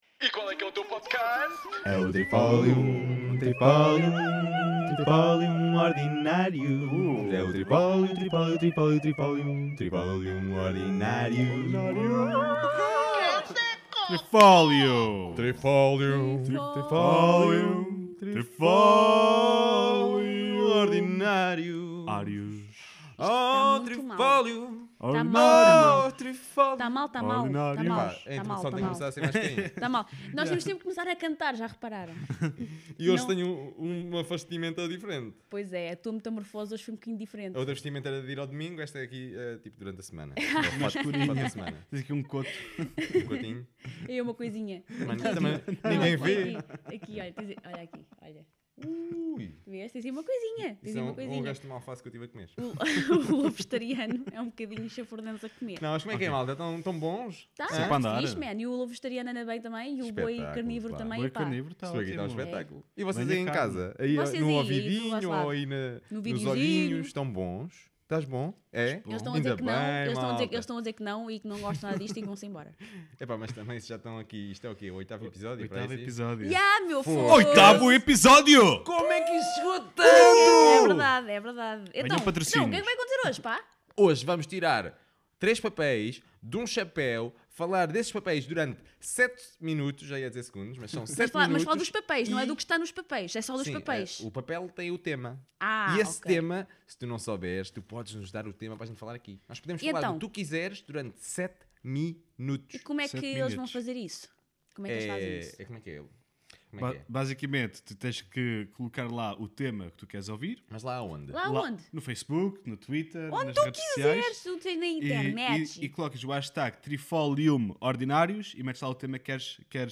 Trifolium Ordinarius By Trifolium Ordinarius 3 amigos: um lobo vegetariano, um boi carnivoro e um cocó fazem um podcast sobre sustentabilidade, permacultura, alguma ordinarice e muita diversão.